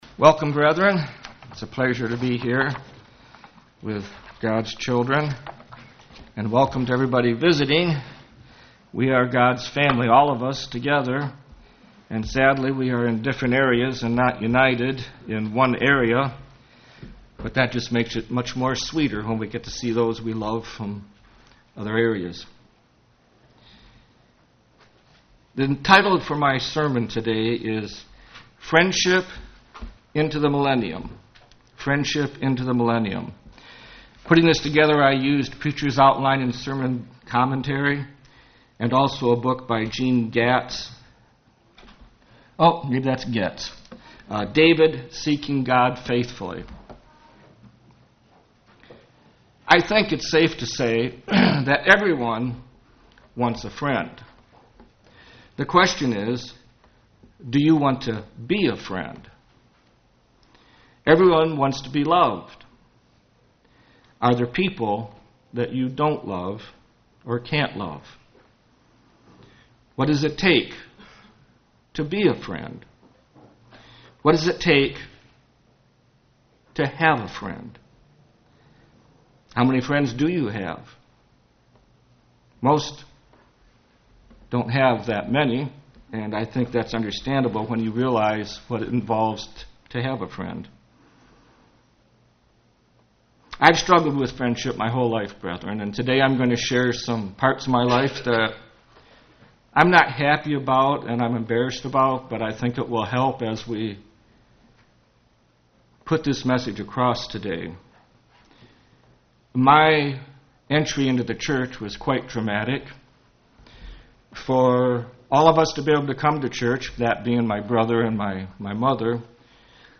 Given in Ann Arbor, MI